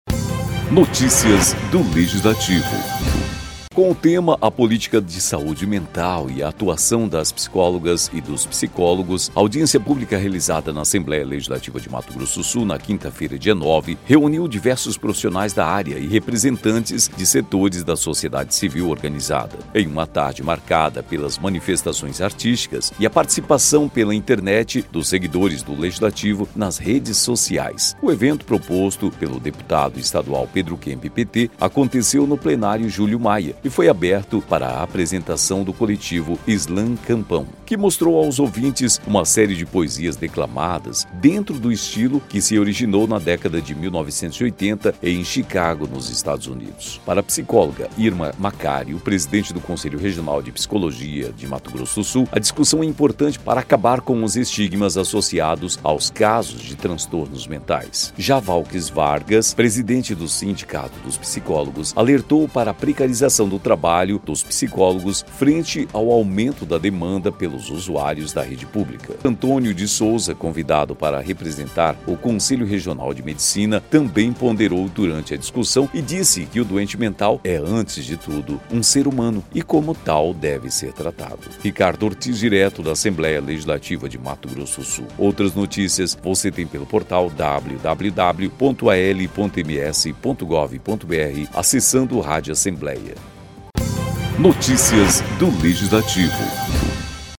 Com o tema “A Política de Saúde Mental e a atuação das Psicólogas e dos Psicólogos”, a audiência pública realizada na Assembleia Legislativa de Mato Grosso do Sul nesta quinta-feira (09), reuniu diversos profissionais da área e representantes de setores da sociedade civil organizada em uma tarde marcada pelas manifestações artísticas e a participação pela internet dos seguidores do Legislativo nas redes sociais.